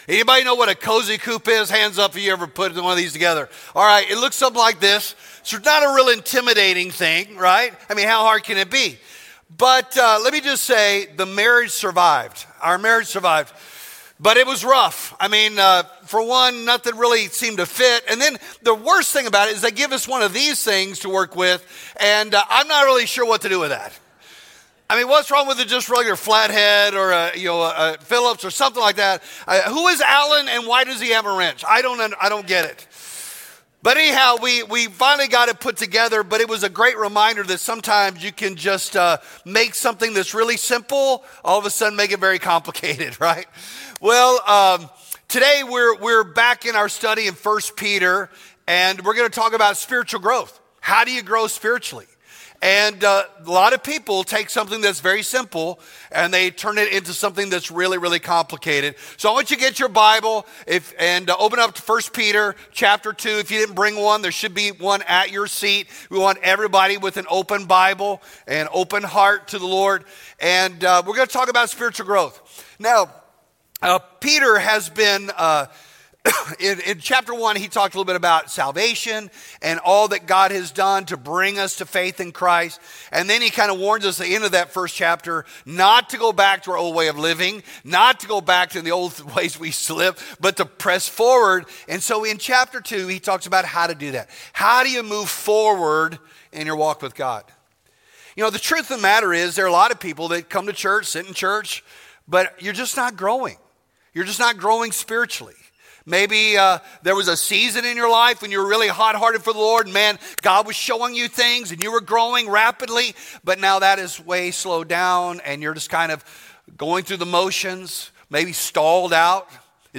Hear the latest sermons from CrossCreek Church!